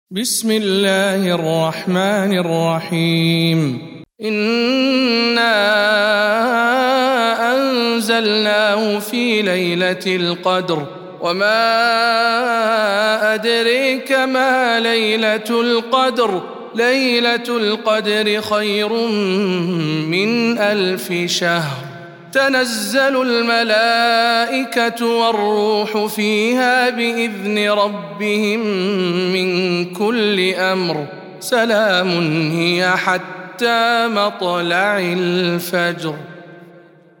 سورة القدر - رواية ابن ذكوان عن ابن عامر